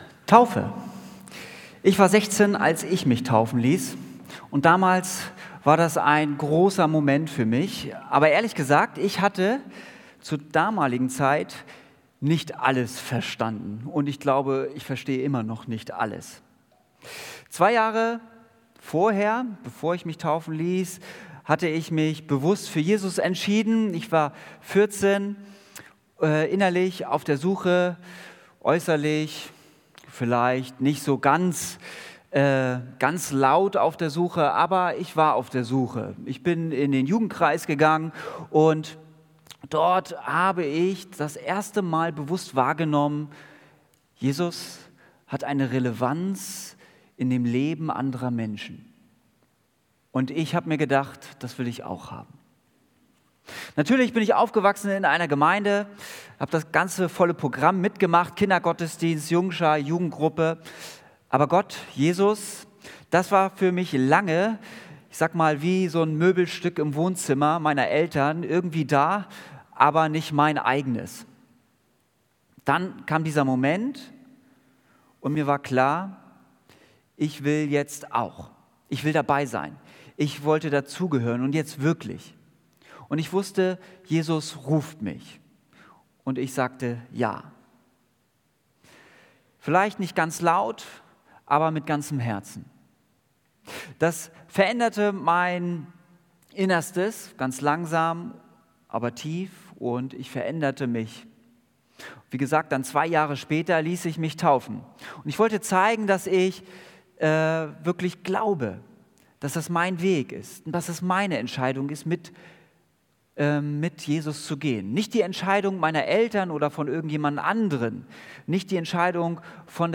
Tauf-Gottesdienst mit Abendmahl
Predigt